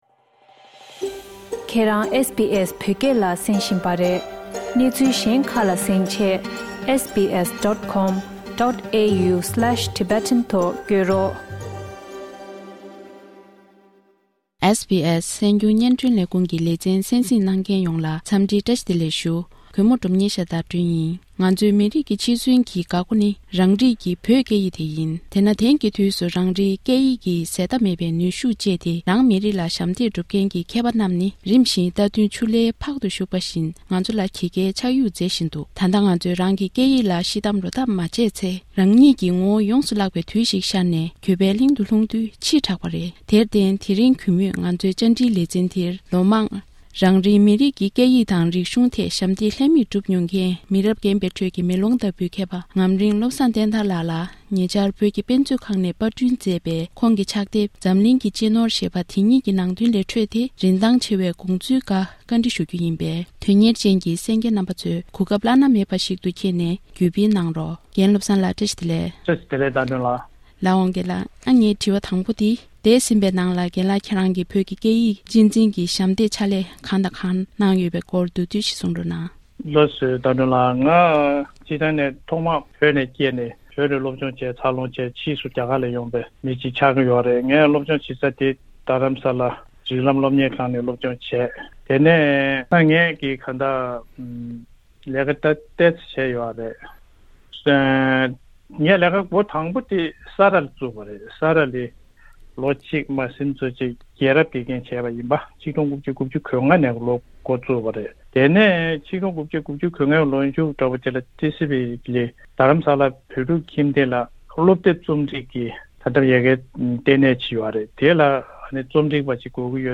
འཛམ་གླིང་གི་སྤྱི་ནོར། community Interview